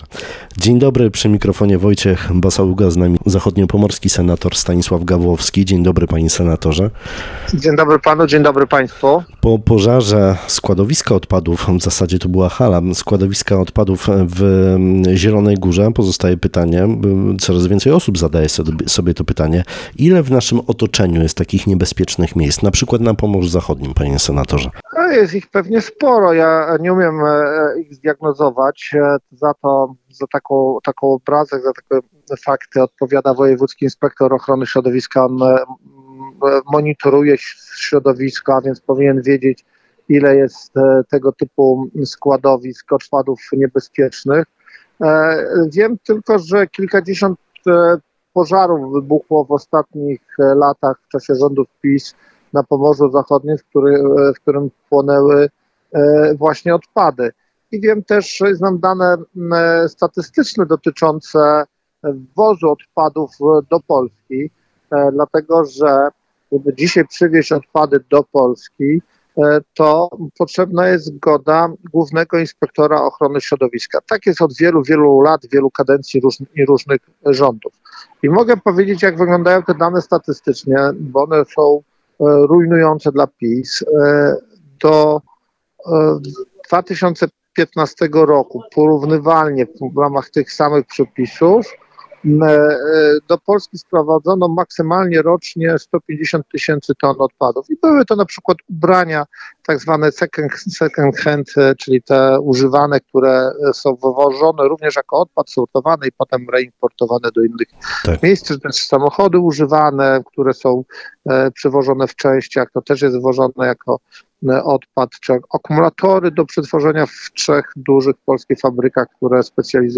Pożar składowiska odpadów pod Zieloną Górą otwiera pytanie – ile podobnych miejsc jest na mapie Polski a także Pomorza Zachodniego. Senator Stanisław Gawłowski mówi, że konkretną liczbę powinien znać Wojewódzki Inspektor Ochrony Środowiska. Przy okazji punktuje partię rządzącą i mówi, że gdy rządziło PO-PSL do Polski nie trafiało tyle odpadów co teraz.